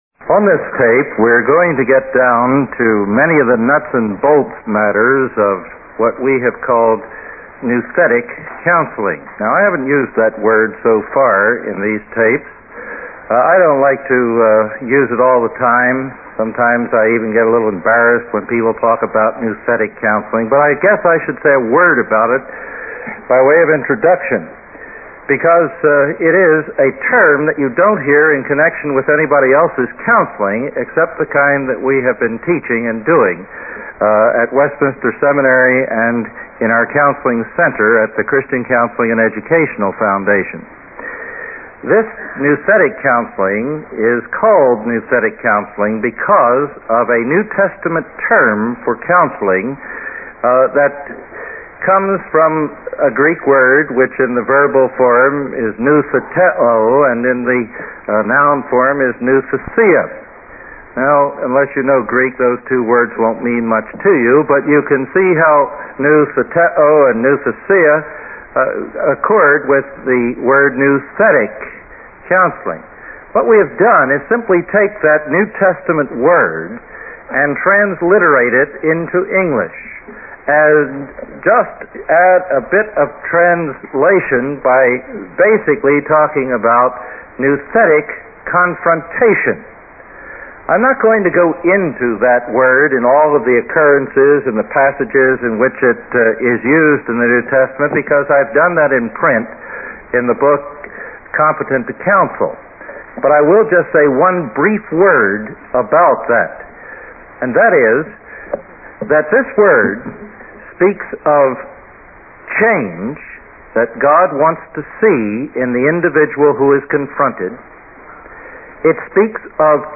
In this classic lecture